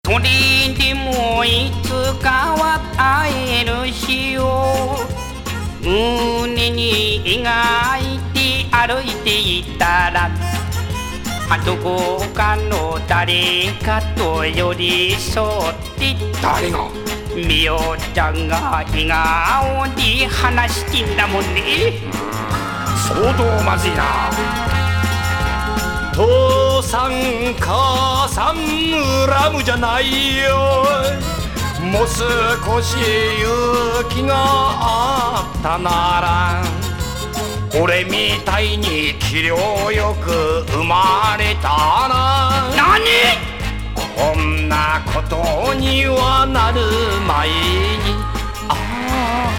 コミックR&B